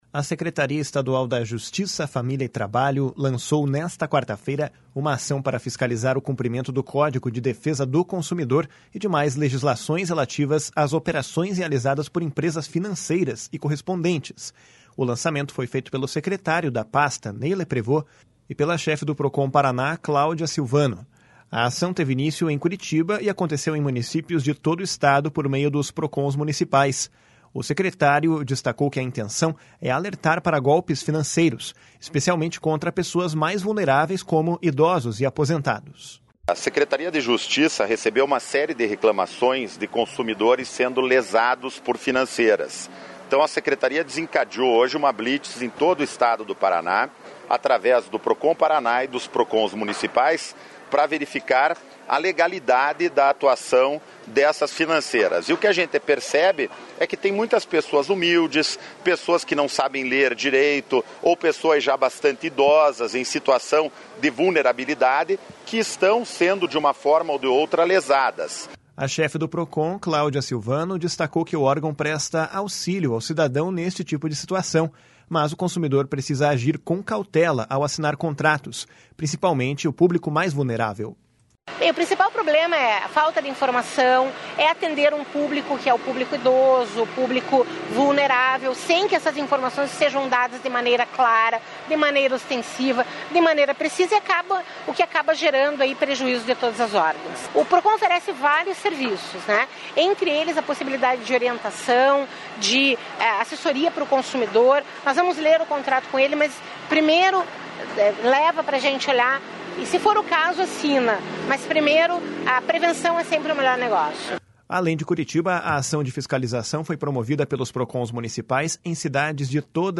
O secretário destacou que a intenção é alertar para golpes financeiros, especialmente contra pessoas mais vulneráveis, como idosos e aposentados. // SONORA NEY LEPREVOST //
// SONORA CLAUDIA SILVANO //